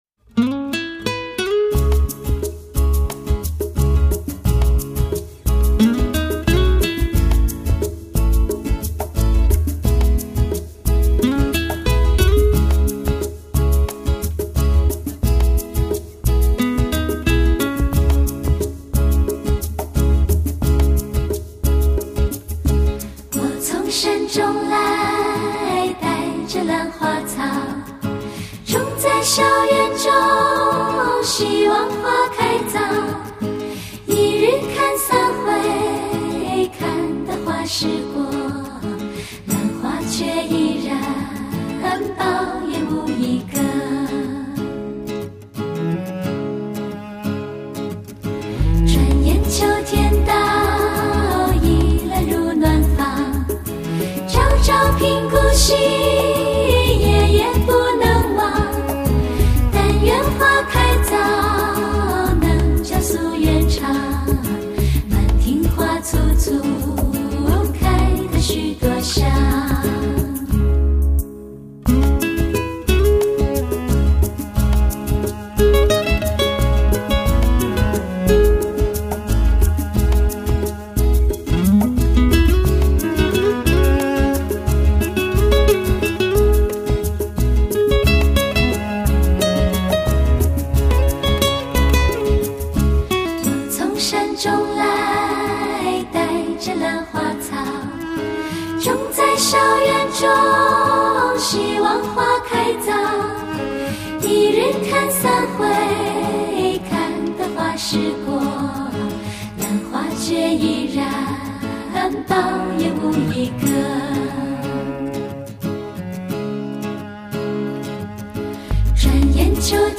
专辑里面有更多男声加入又起到画龙点睛的作用
厚润弹性的低频、足可令你随之起舞的节奏、清新悦耳的演唱、你一定会随之哼唱的旋律，原来这些歌可以这样表达的 ……还是